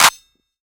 SOUTHSIDE_snare_metal_clap_player.wav